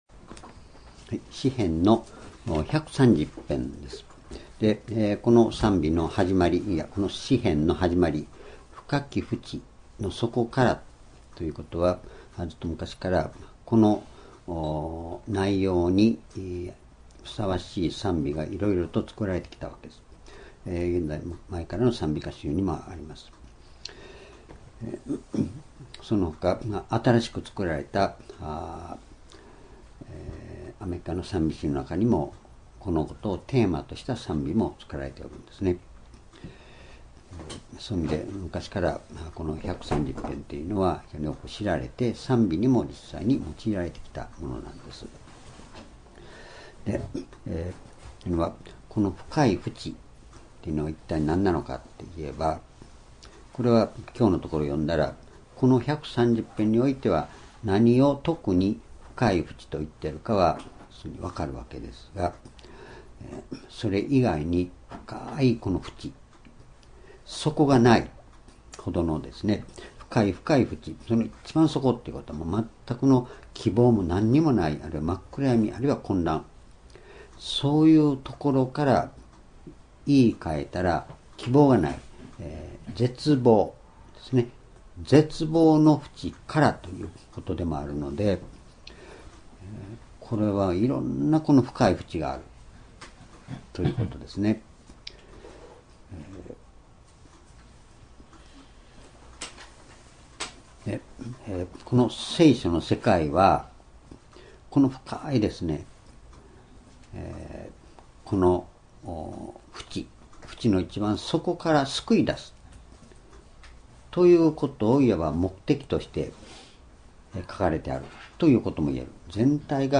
2016年10月25日 移動夕拝 詩篇130編 ｢深き淵より｣